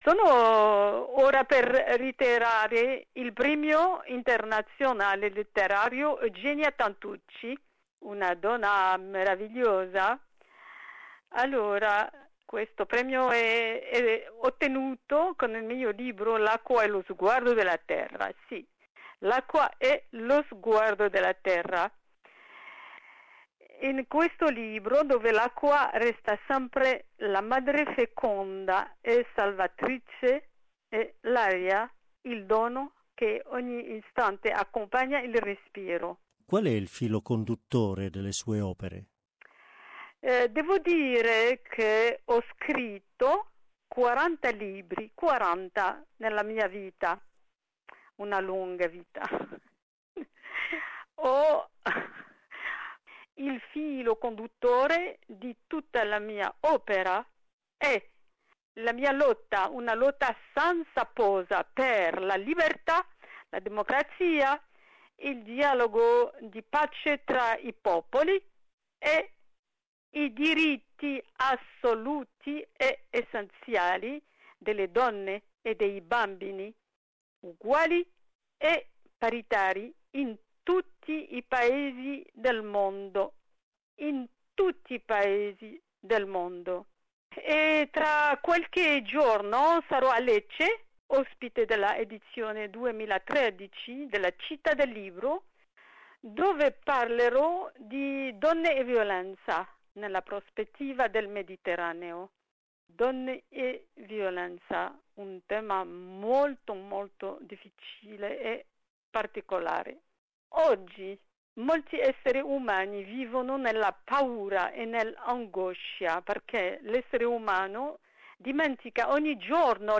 In questa intervista ci parla del premio e della sua lunga e brillante esperienza di scrittrice.